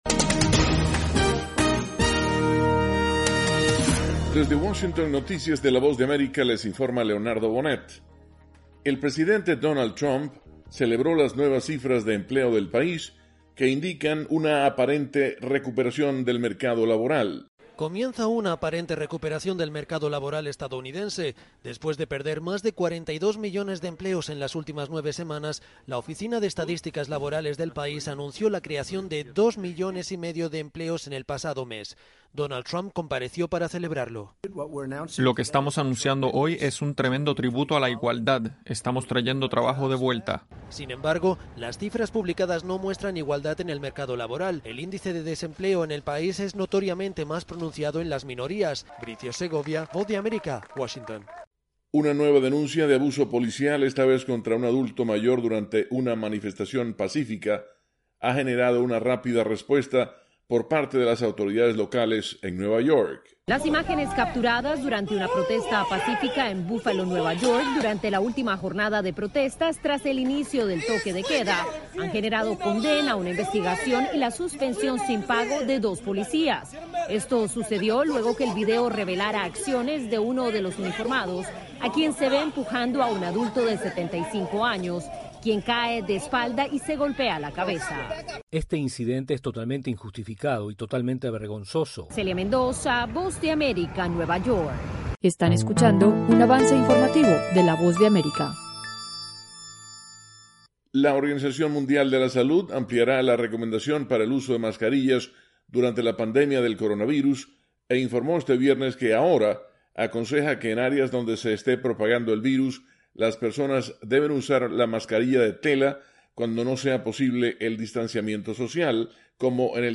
Segmento informativo de 3 minutos de duración con noticias de Estados Unidos y el resto del mundo.